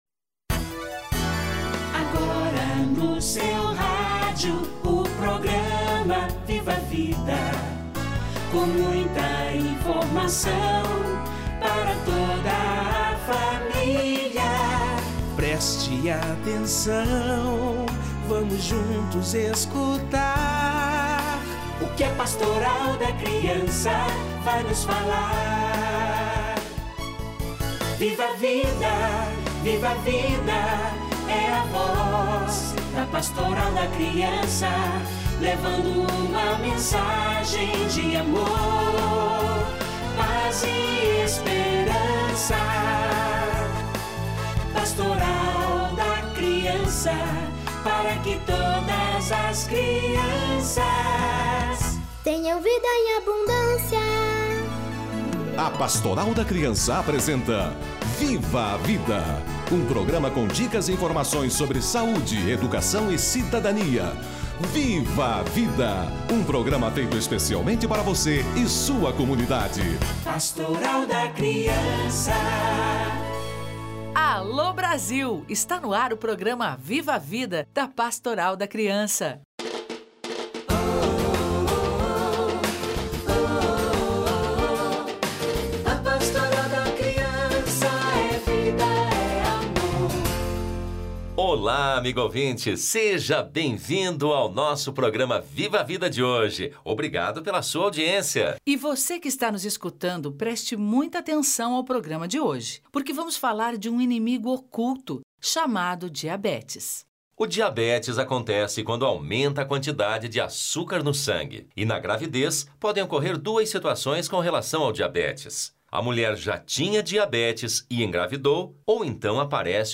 Diabetes gestacional - Entrevista